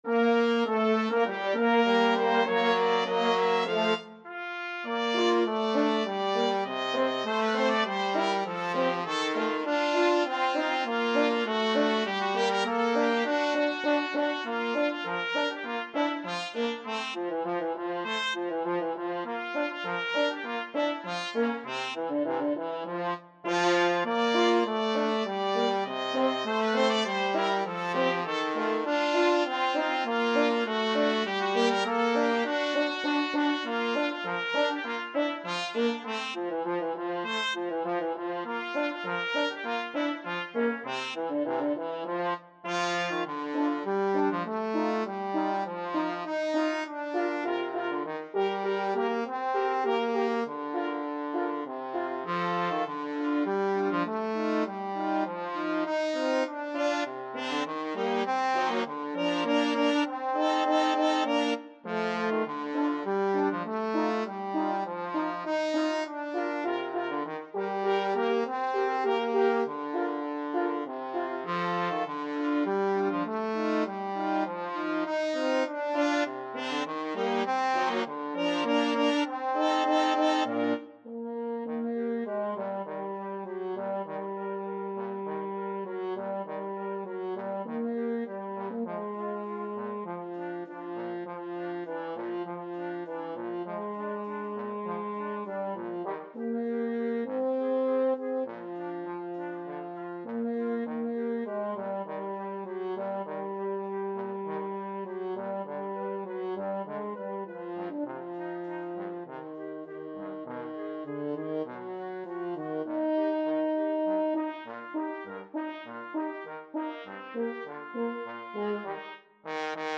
TrumpetFlute
French HornClarinet
TromboneAlto Saxophone
2/2 (View more 2/2 Music)
Quick March = c.100